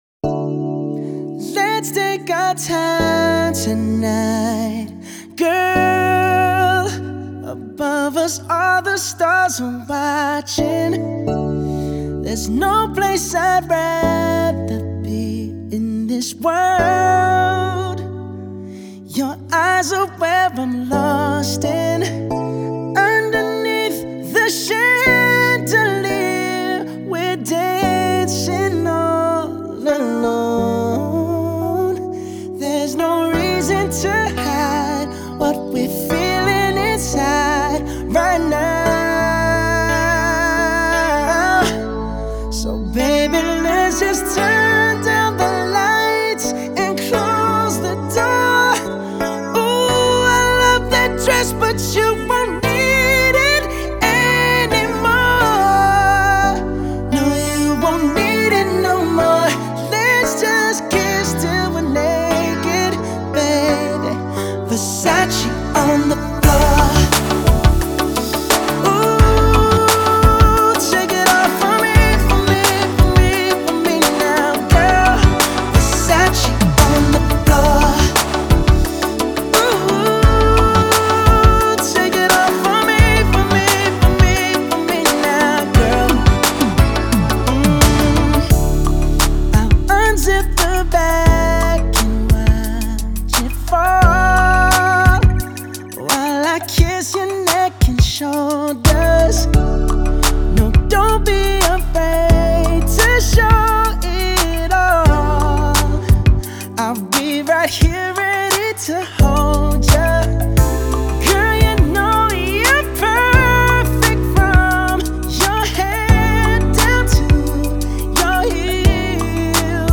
is that eighties slow jam